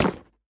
Minecraft Version Minecraft Version 1.21.5 Latest Release | Latest Snapshot 1.21.5 / assets / minecraft / sounds / block / nether_bricks / break6.ogg Compare With Compare With Latest Release | Latest Snapshot